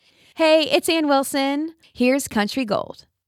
LINER Anne Wilson (Here's Country Gold)
LINER-Anne-Wilson-Heres-Country-Gold.mp3